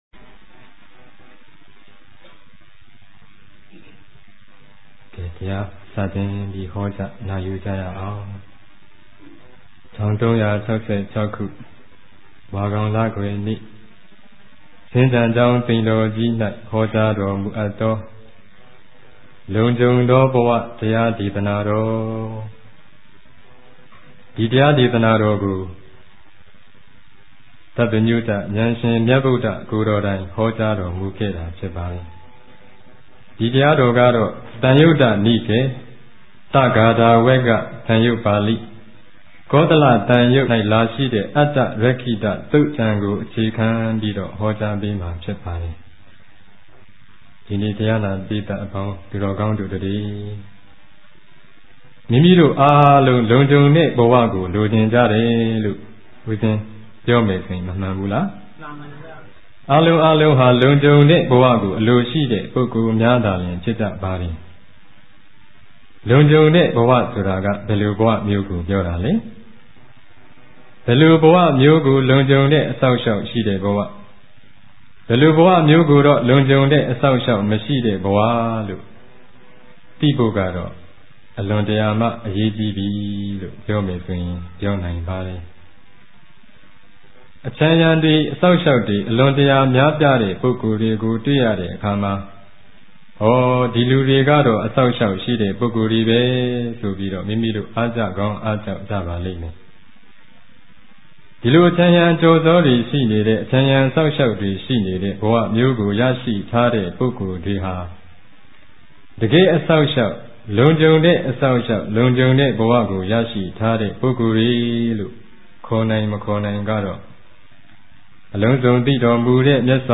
၀ိပဿနာတရားစခန်းများတွင် ဟောကြားခဲ့သော တရားတော်များ